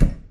lava.ogg